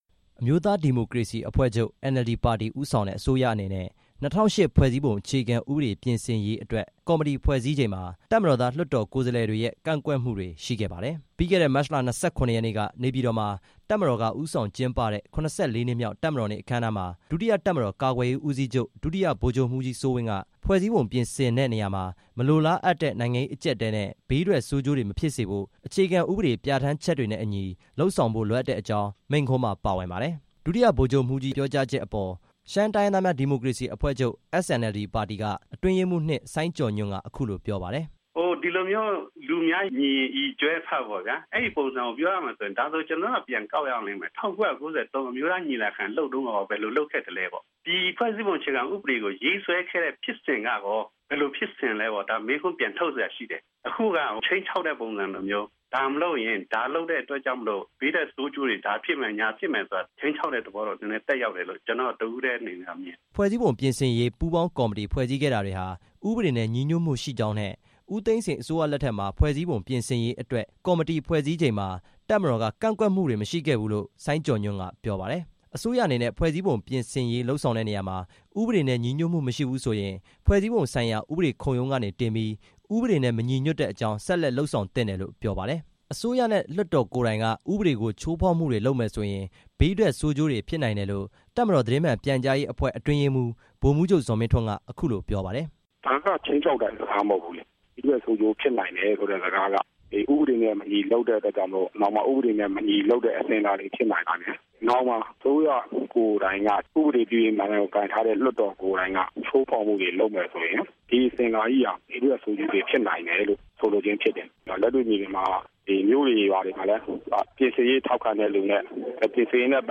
ဒီနေ့ နေပြည်တော် သမ္မတအိမ်တော်မှာ ပြုလုပ်တဲ့ သတင်းစာရှင်းလင်းပွဲမှာ ဦးဇော်ဌေး ပြောခဲ့တာပါ။